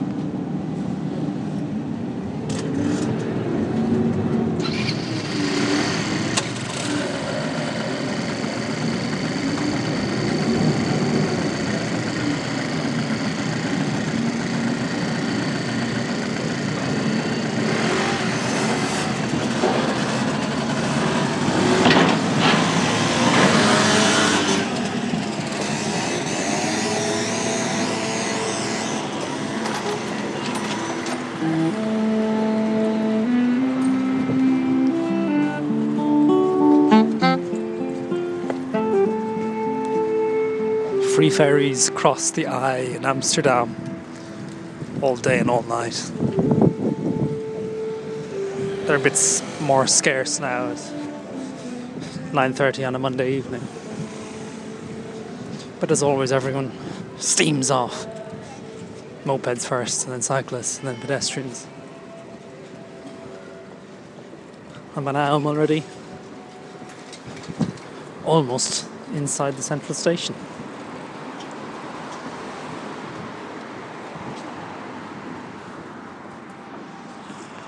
Disembarking
Amsterdam pont ferry on the Ij